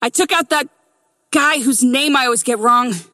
McGinnis voice line - I took out that... guy whose name I always get wrong.